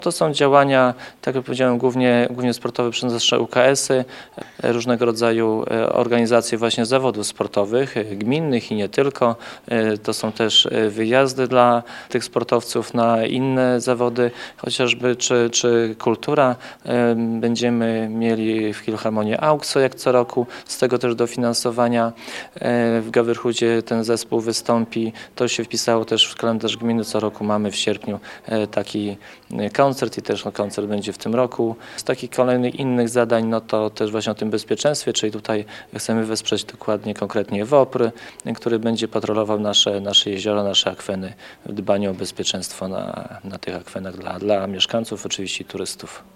O szczegółach mówi Zbigniew Mackiewicz, wójt gminy Suwałki.